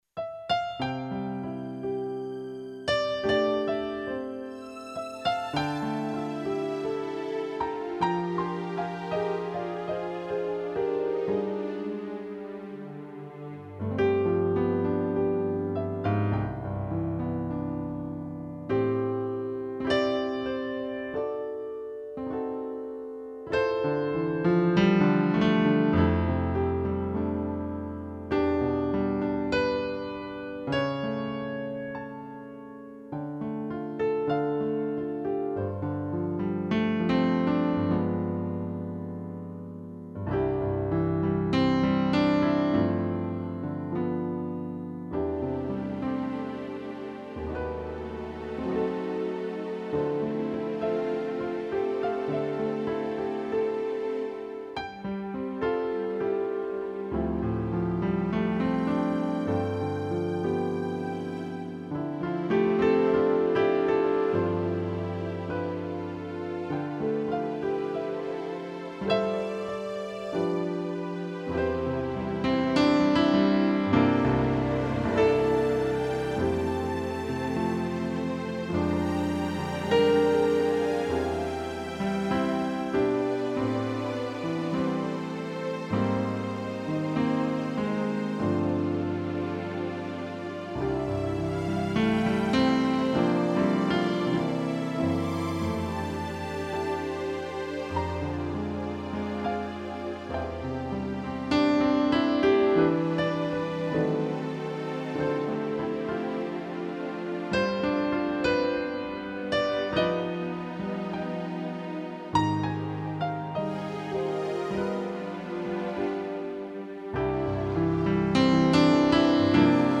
piano e cordas